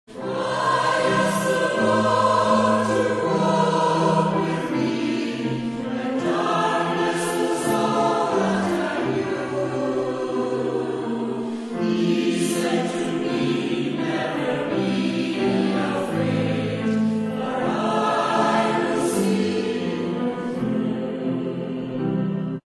Maybole Churches have a long history of Choral Singing.
Praise music performed in these historic buildings.